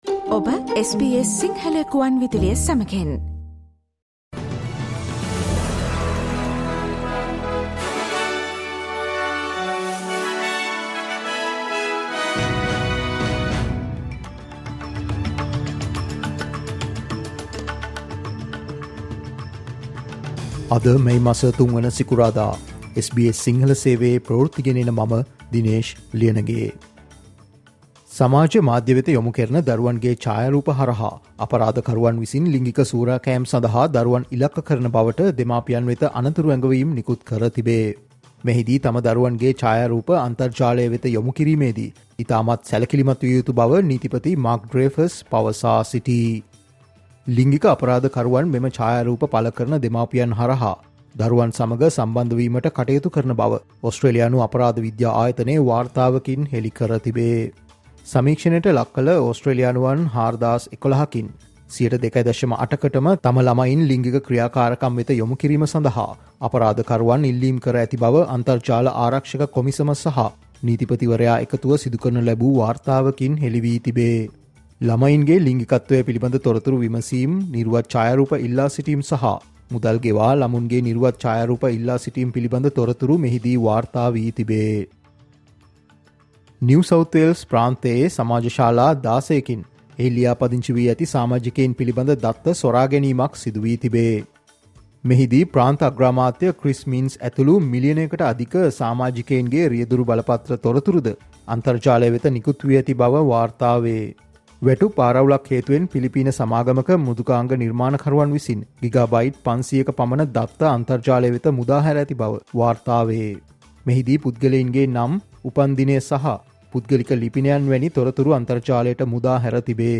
Australian news in Sinhala, foreign and sports news in brief - listen, Sinhala Radio News Flash on Friday 03 of May 2024